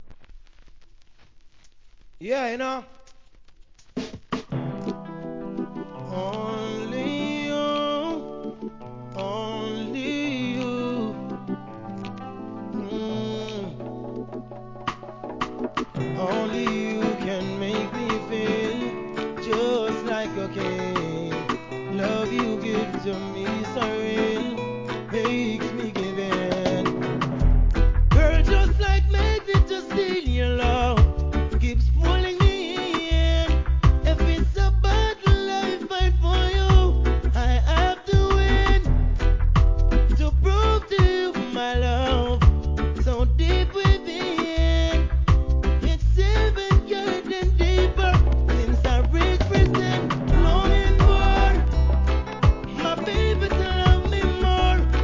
REGGAE
ミディアムの超BIG HITリディム!!